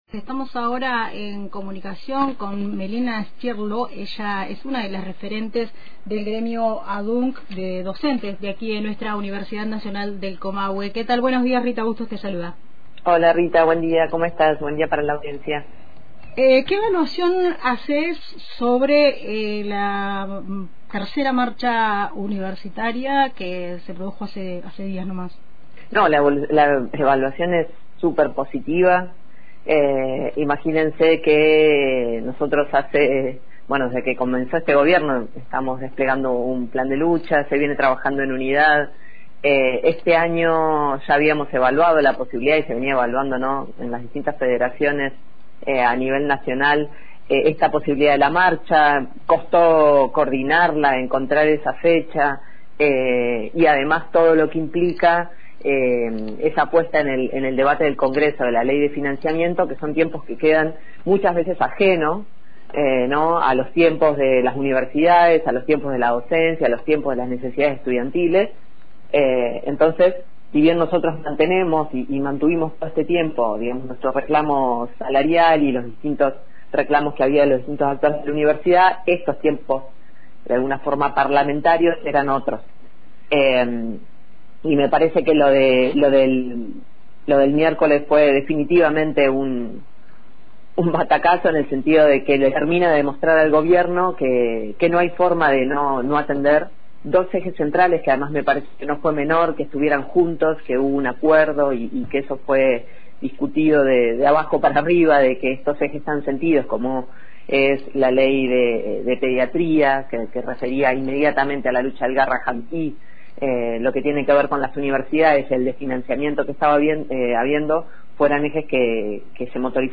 En diálogo con Radio Antena Libre, destacó que la movilización, que en Neuquén reunió 15 cuadras de manifestantes, fue un “batacazo” que visibilizó el desfinanciamiento de las universidades y el reclamo por la Ley de Financiamiento Universitario efectivo.